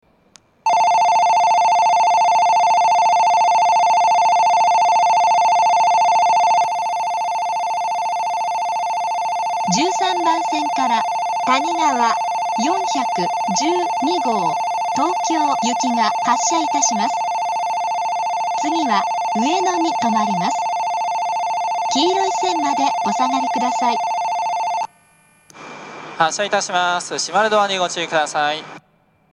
標準的な音程の発車ベルを使用していますが、１５・１６番線の発車ベルは音程が低いです。
１３番線発車ベル たにがわ４１２号東京行きの放送です。